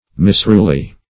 misruly - definition of misruly - synonyms, pronunciation, spelling from Free Dictionary Search Result for " misruly" : The Collaborative International Dictionary of English v.0.48: Misruly \Mis*rul"y\, a. Unruly.